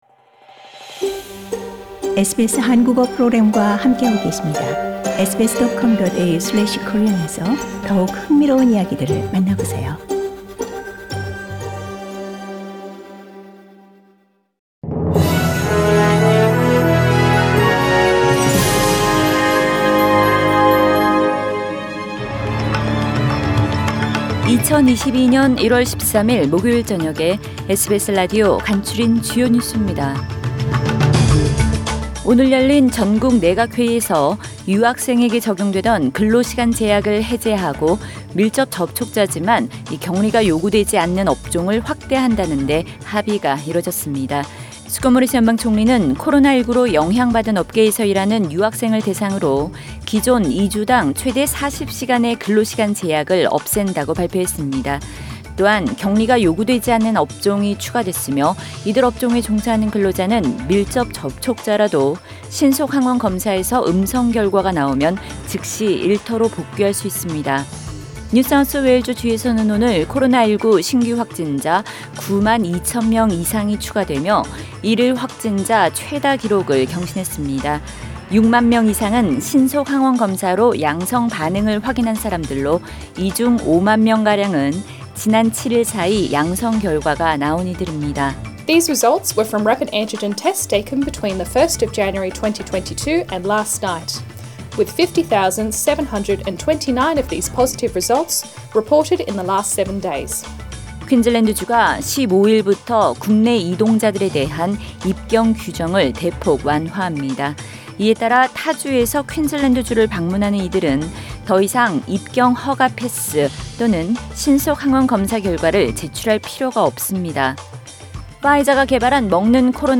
2022년 1월 13일 목요일 저녁의 SBS 뉴스 아우트라인입니다.